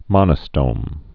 (mŏnə-stōm)